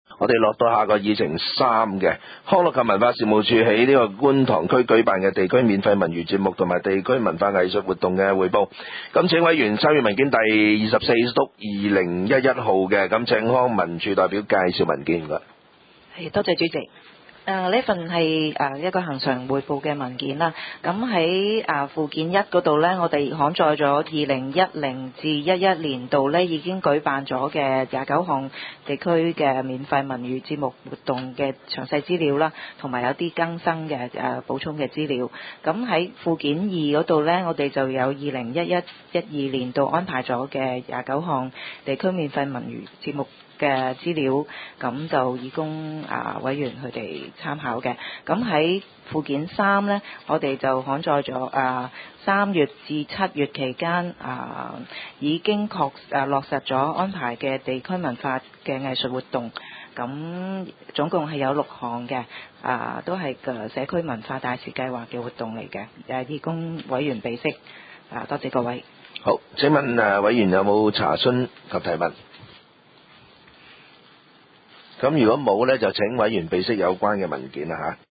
第二十三次會議議程
九龍觀塘同仁街6號觀塘政府合署3樓觀塘民政事務處會議室